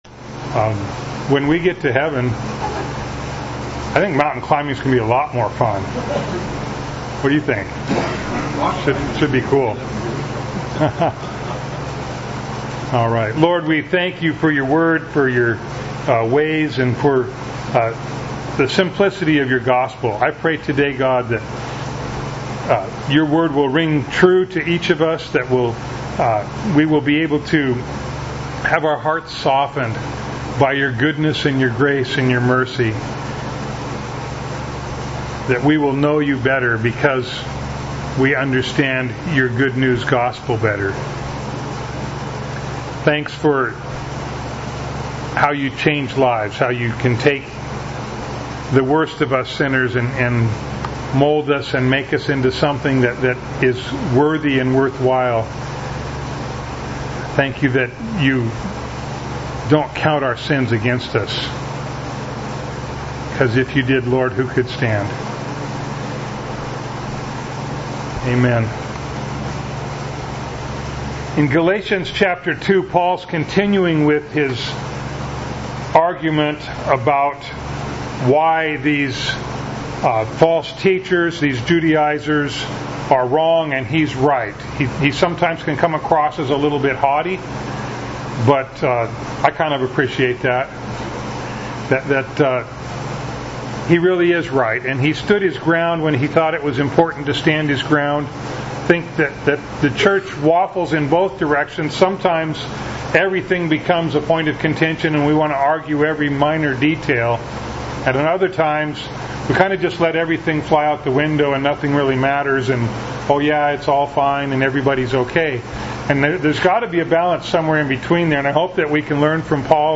Bible Text: Galatians 2:1-10 | Preacher